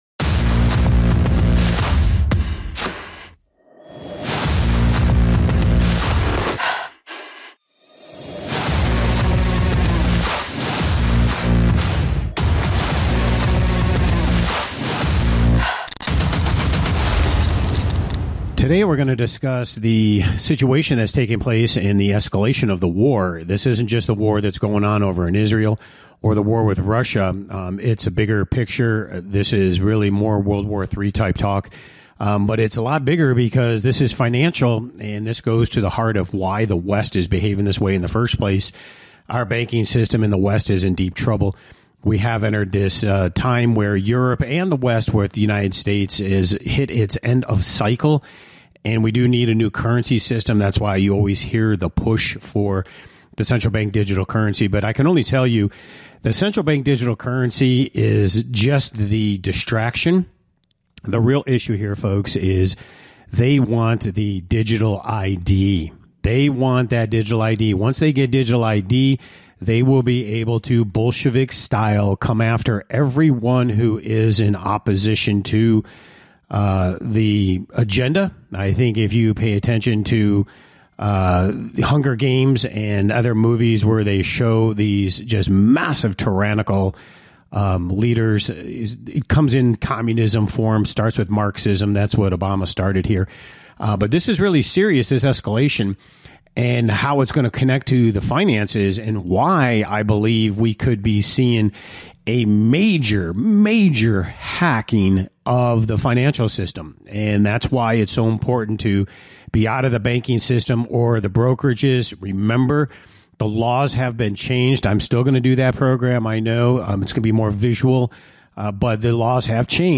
Talk Show Episode, Audio Podcast, Rigged Against You and War Escalation and Your Money on , show guests , about War Escalation and Your Money, categorized as Business,Investing and Finance,Management,News,Self Help,Society and Culture,Technology